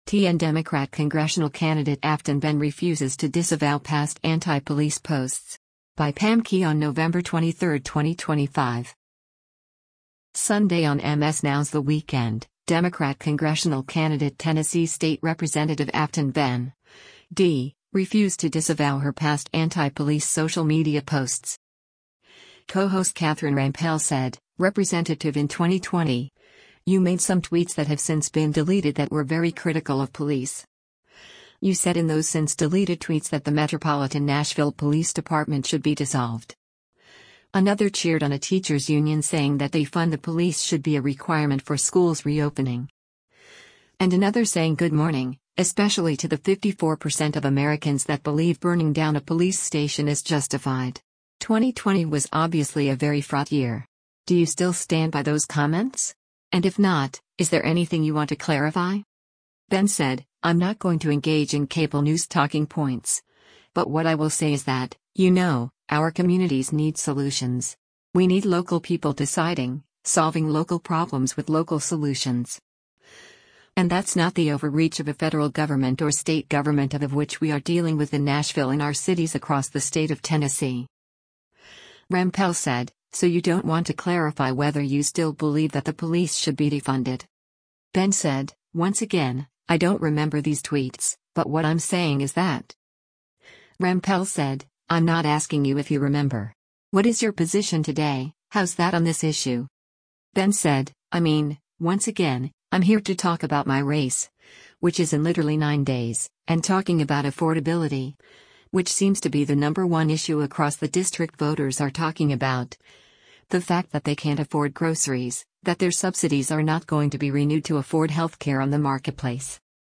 Sunday on MS NOW’s “The Weekend,” Democrat congressional candidate Tennessee State Rep. Aftyn Behn (D) refused to disavow her past anti-police social media posts.